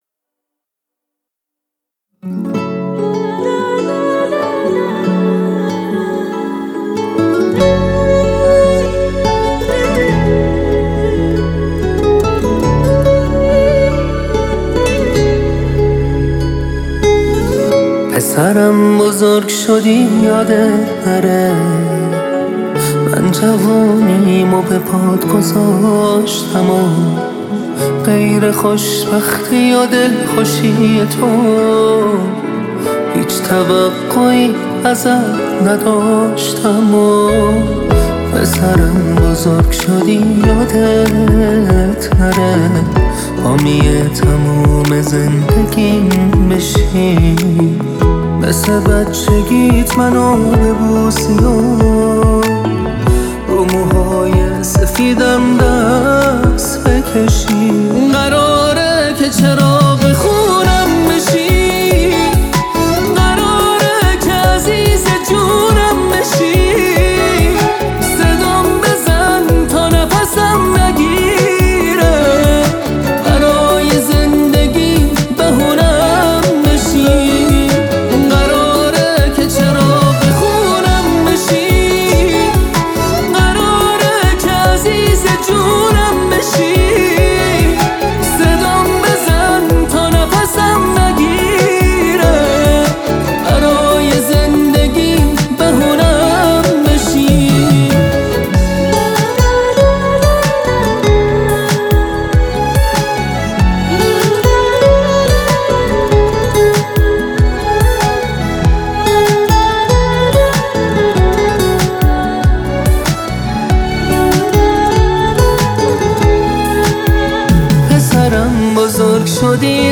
دانلود آهنگ پاپ